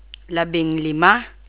Labing lima